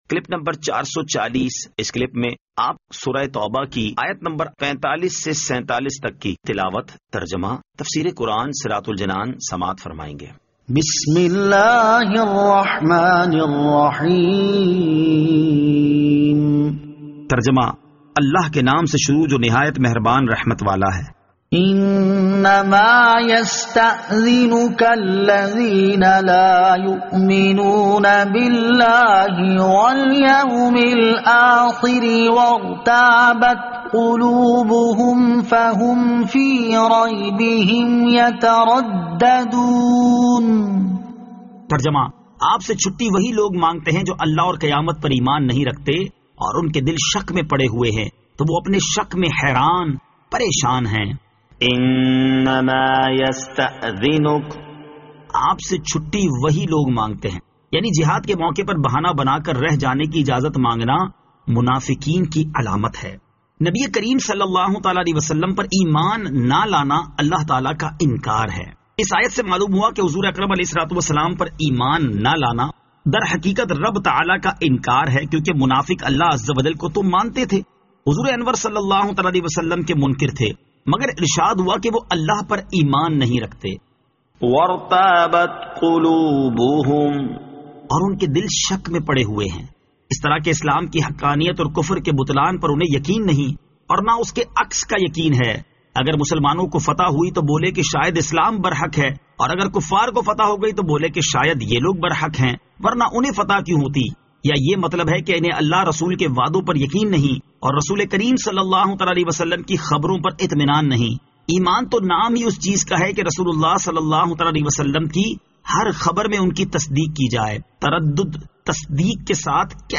Surah At-Tawbah Ayat 45 To 47 Tilawat , Tarjama , Tafseer